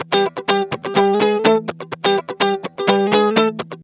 Electric Guitar 07.wav